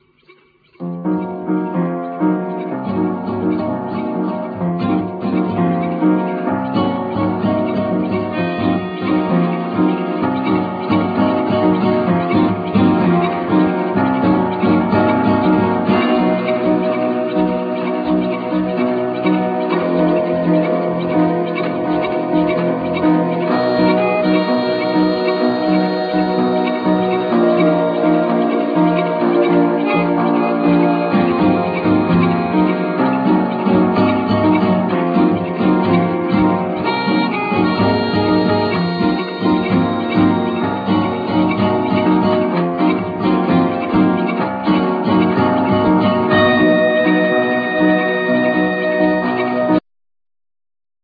Ac.guitar,Organ,Piano,Bass,Melodica,Flute,Xylophne
Trumpet,Tronbone,Contrabass
Sanza,Steel-drum